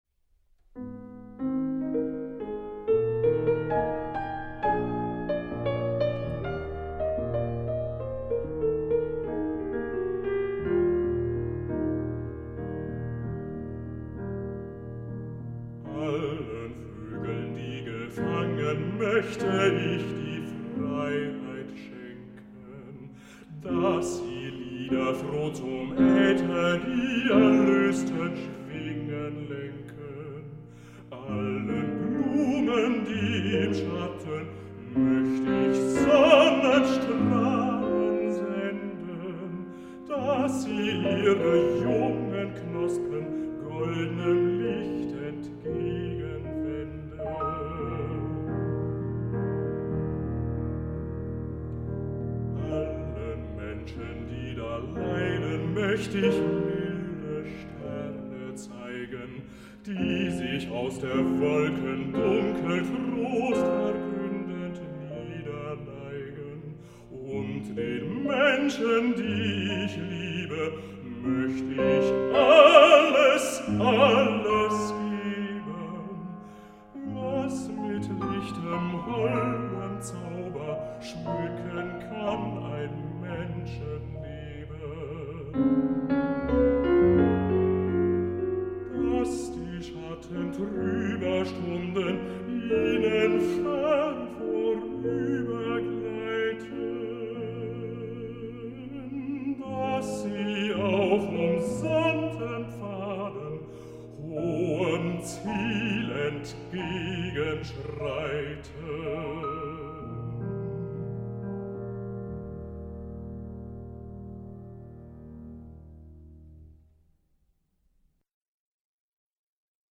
für Bariton und Klavier
für zwei Klaviere
Bariton
Klavier & Klavierduo
Aufnahme: Musikhochschule Würzburg, 26.10.2000
Bösendorfer 275, 2 x Steinway & Sons D-274